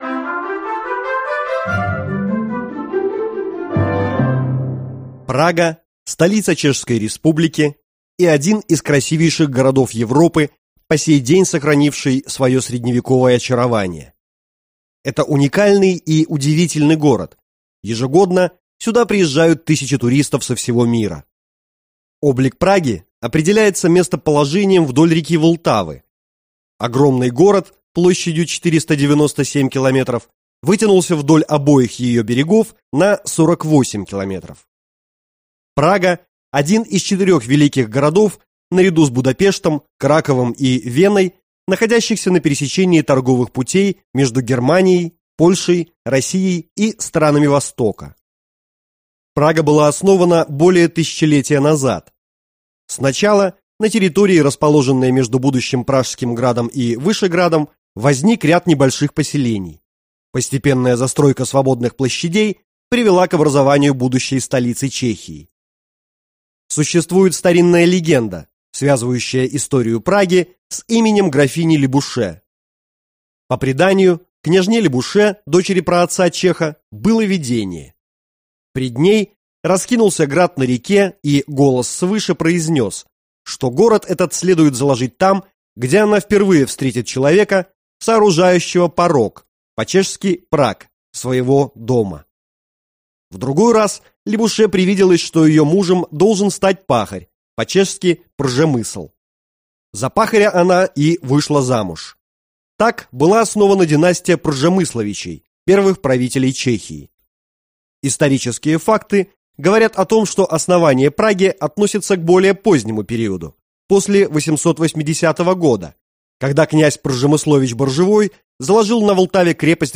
Аудиокнига Путеводитель по Праге | Библиотека аудиокниг